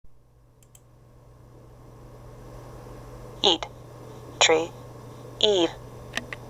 [イー] eat, tree, Eve